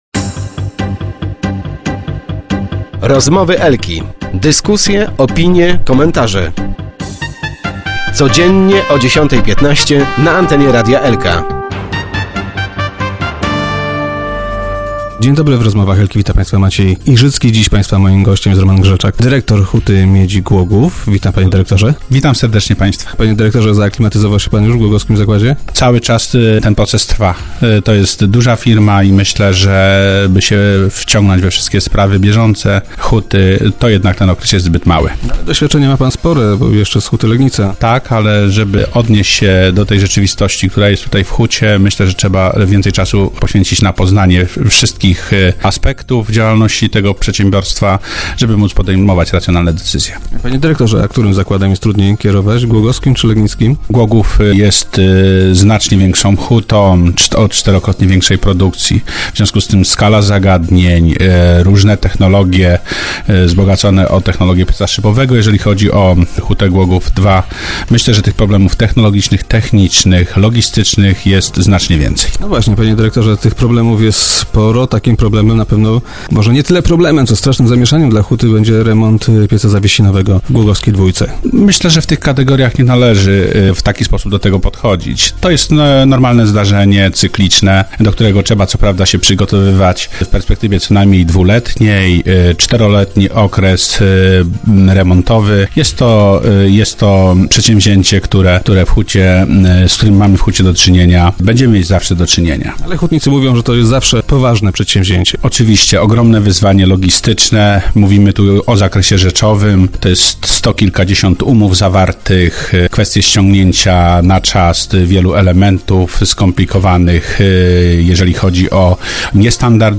- Prowadzone prace nie wpłyną na produkcję i na zatrudnienie w zakładzie - zapewnił dzisiejszy gość Rozmów Elki.